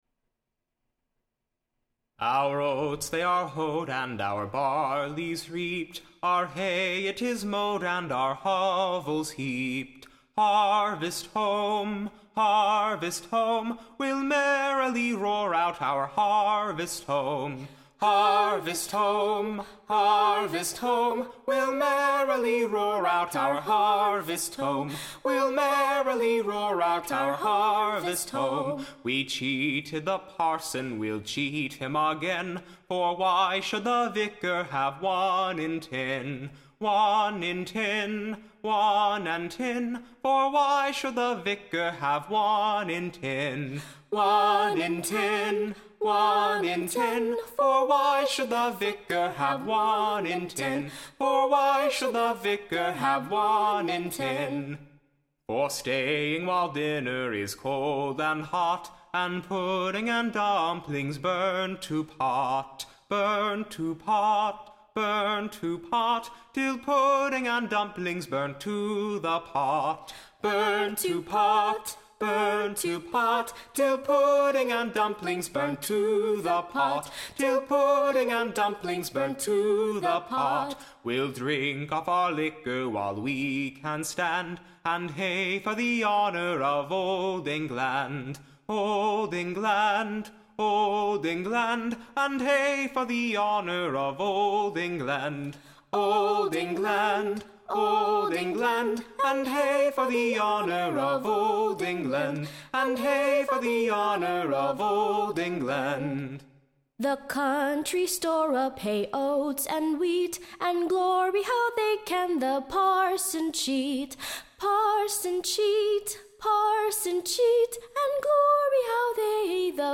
Recording Information Ballad Title The Country Farmer's Vain-glory; in a New Song of / Harvest Home: / Together with an Answer to their undecent Behaviour. Tune Imprint Sung to a New Tune much in Request.